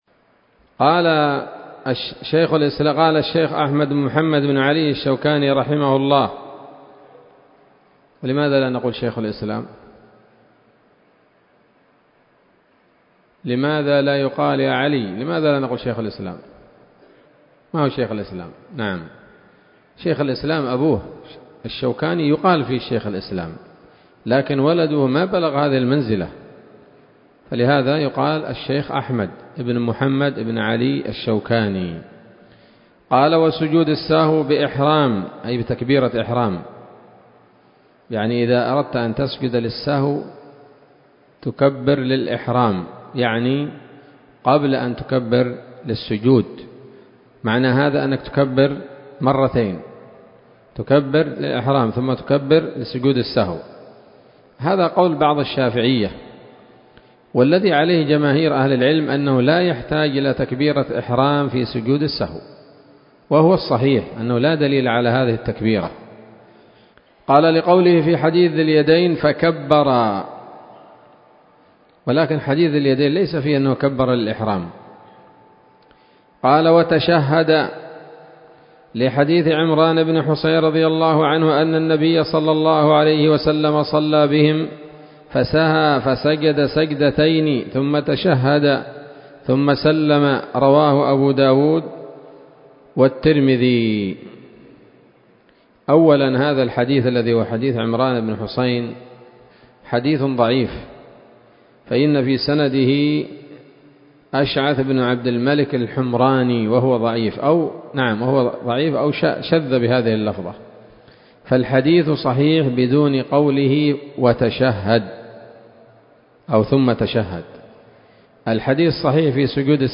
الدرس الثالث والثلاثون من كتاب الصلاة من السموط الذهبية الحاوية للدرر البهية